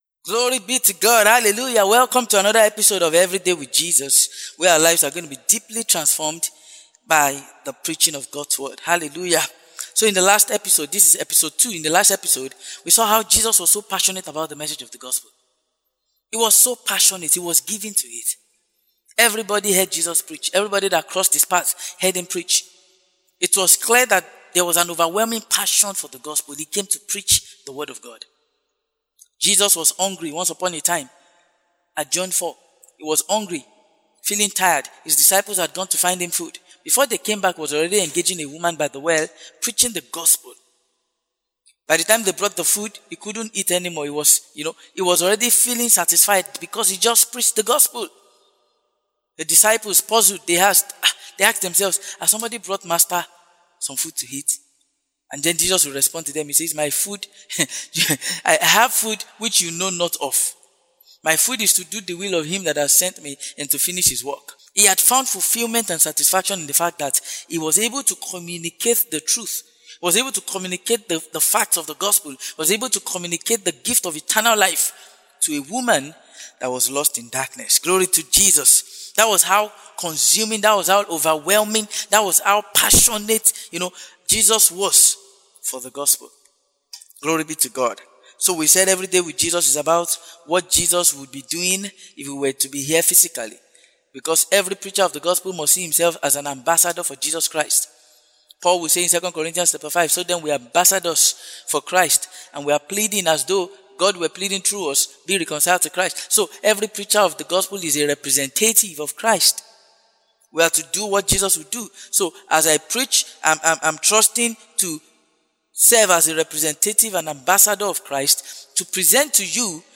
This is the foundation laying series for the Everyday with Jesus Broadcast. We shared on what to expect by defining what the word of God is as seen in the ministry of Christ (in the gospels) & the ministry of the Apostles (in the book of Acts).